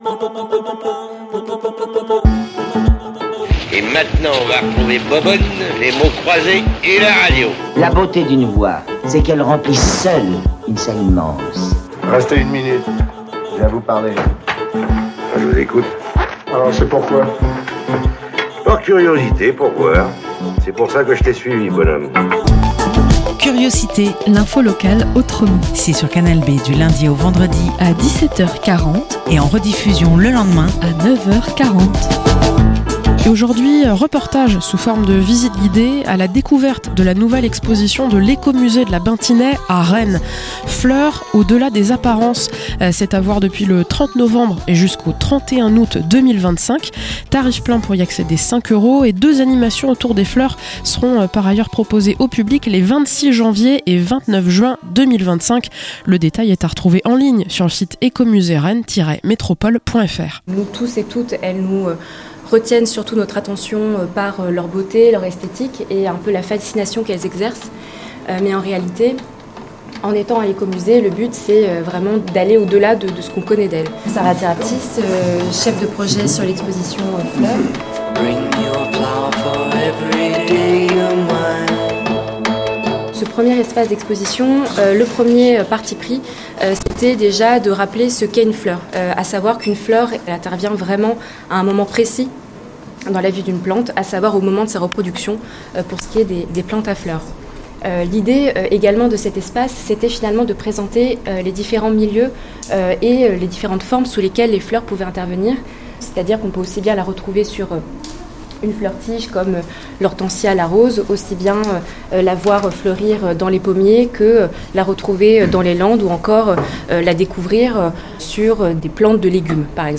- Reportage sous forme de visite guidée à la découverte de la nouvelle exposition de l'Ecomusée de la Bintinais.